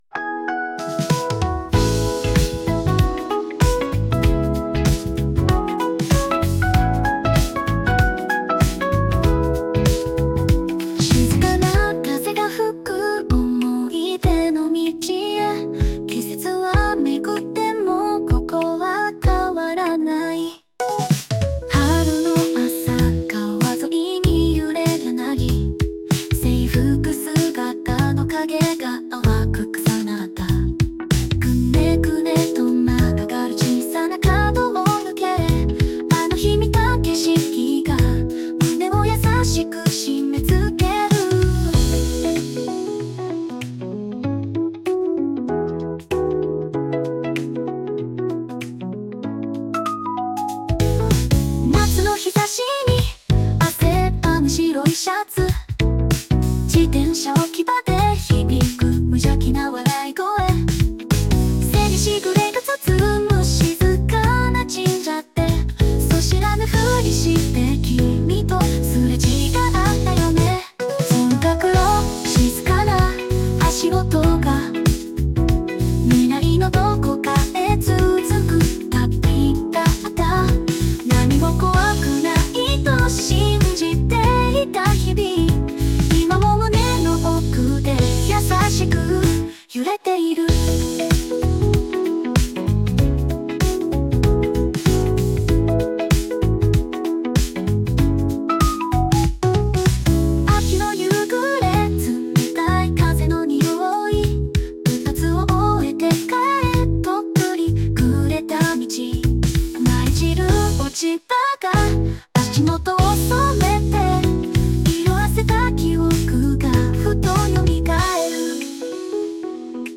＊本楽曲は Suno AI の有料プランにて生成されました＊